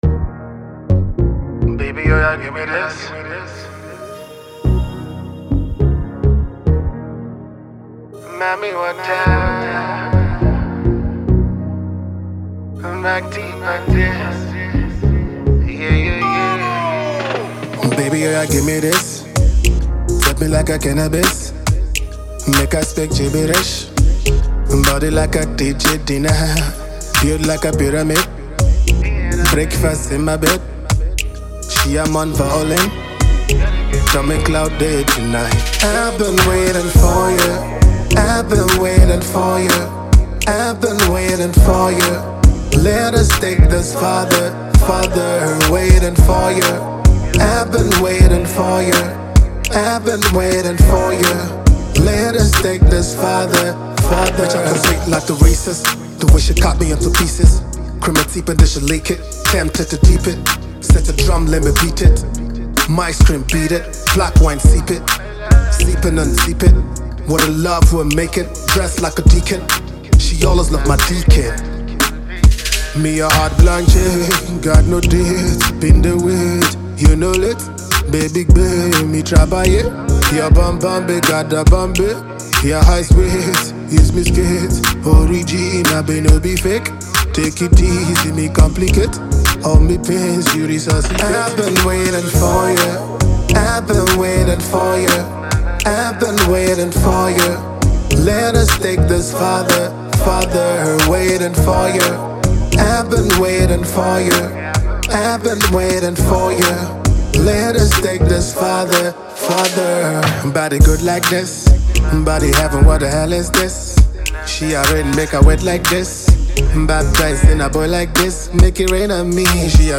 Afro Fusion
soul soothing love song